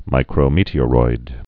(mīkrō-mētē-ə-roid)